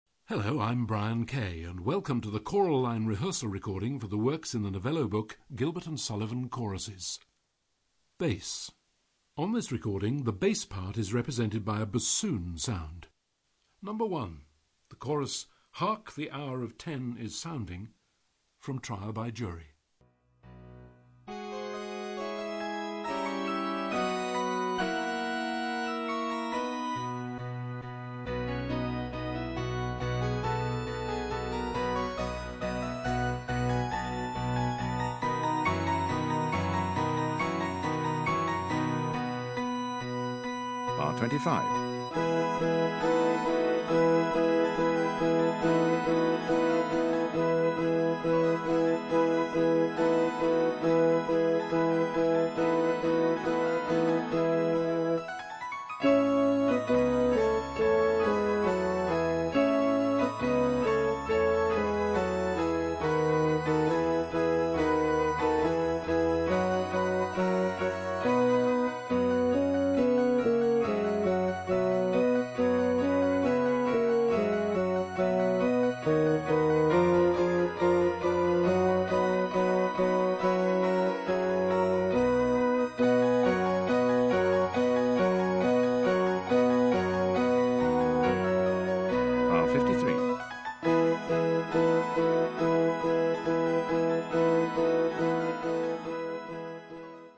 Bass
High Quality made by BBC Sound Engineer
Easy To Use narrator calls out when to sing
Don't Get Lost narrator calls out bar numbers
Be Pitch Perfect hear the notes for your part
Vocal Entry pitch cue for when you come in